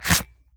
Leather Unholster 001.wav